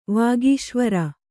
♪ vāgīśvara